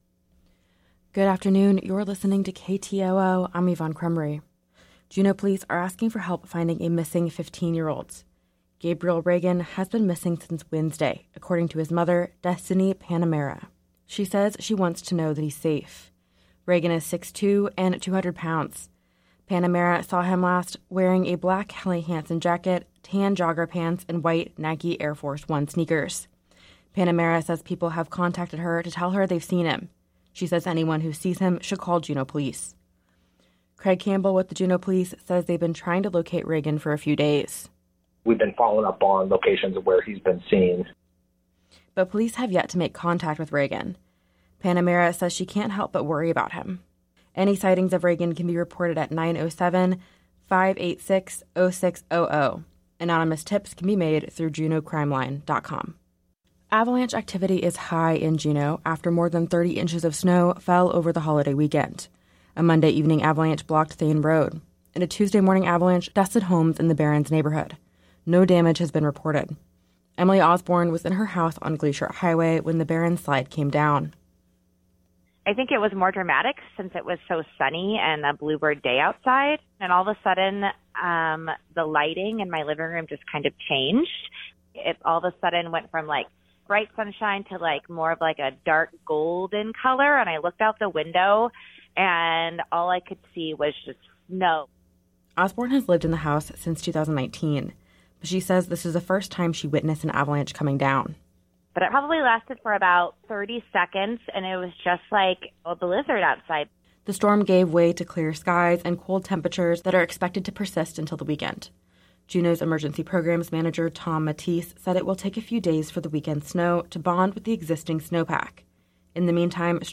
Newscast – Tuesday, Jan. 16, 2024